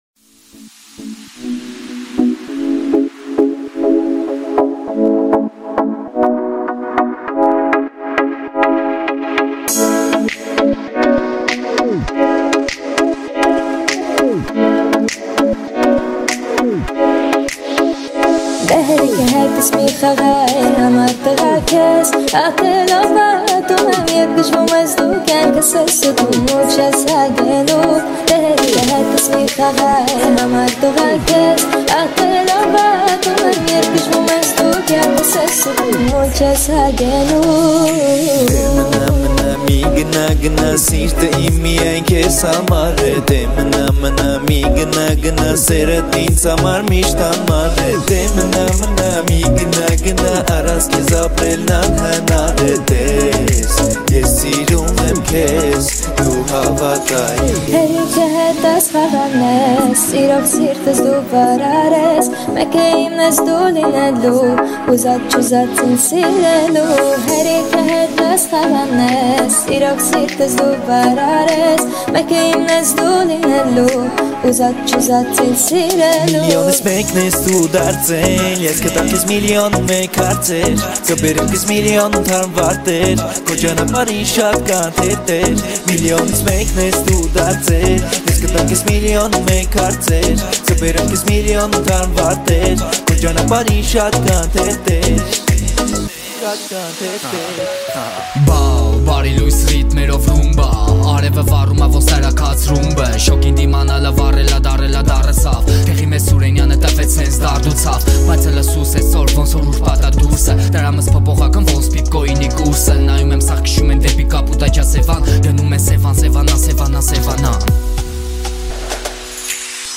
Армянская музыка